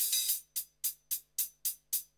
HIHAT LOP2.wav